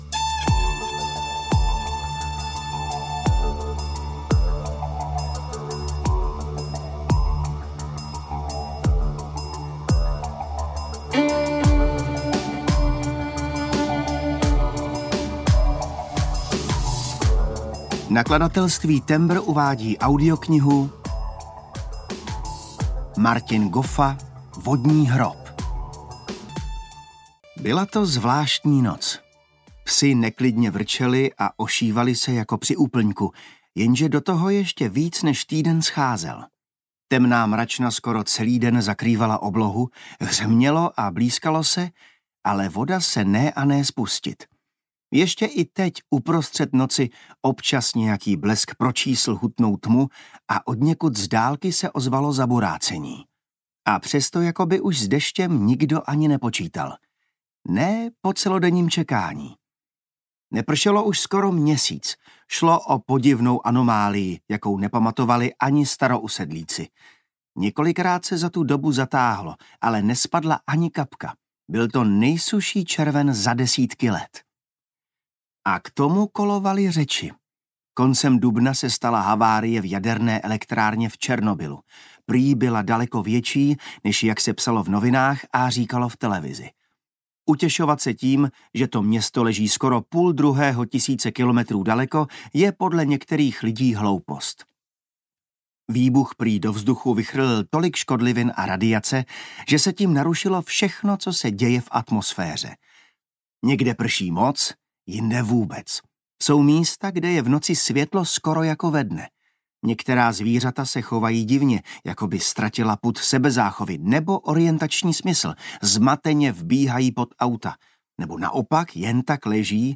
Vodní hrob audiokniha
Ukázka z knihy
• InterpretJan Maxián